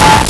Player_Glitch [26].wav